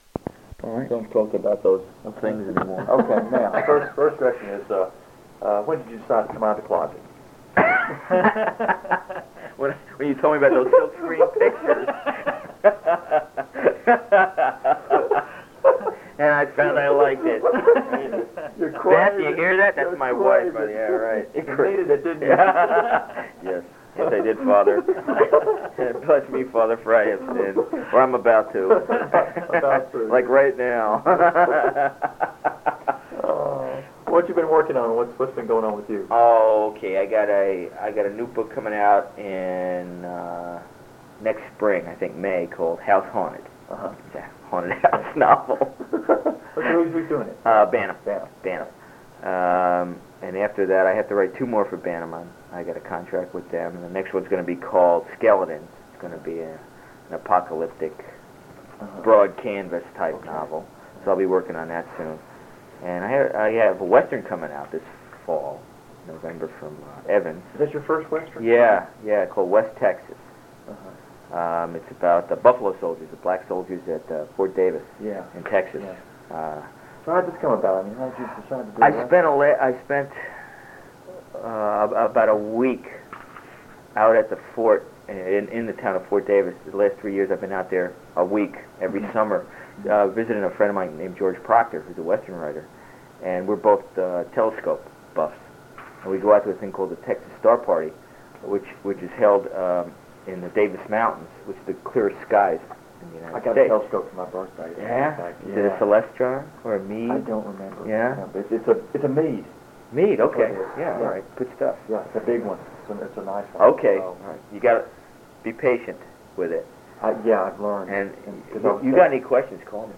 From the Archives: Robert McCammon interviews Al Sarrantonio at WFC 1990 – Robert McCammon
robert-mccammon-interviews-al-sarrantonio-wfc-1990.mp3